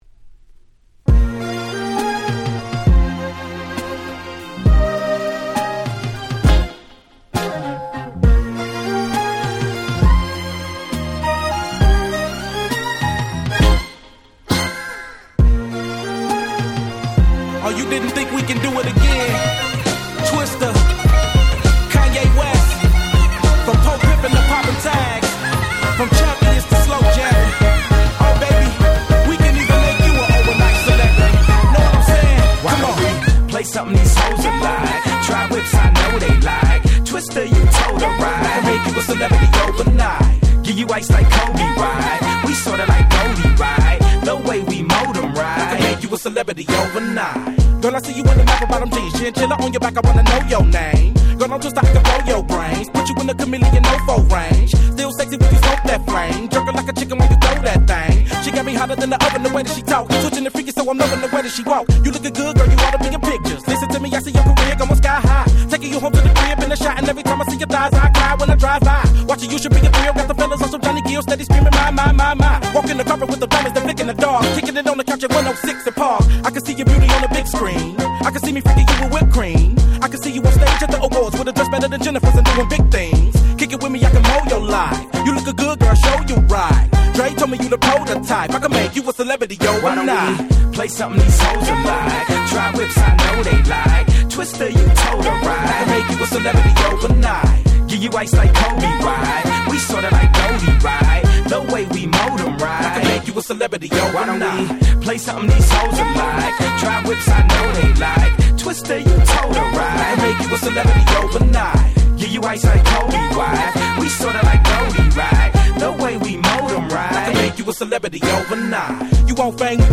04' Super Hit Hip Hop !!